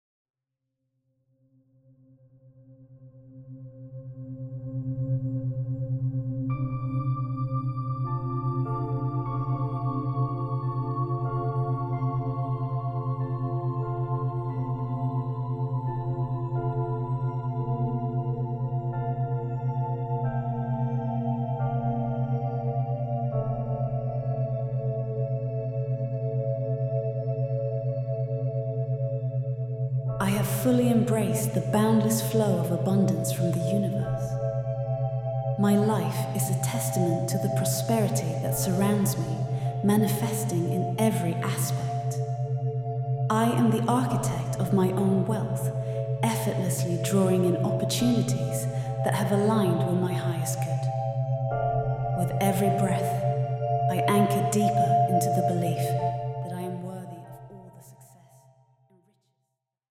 Preview Alpha in C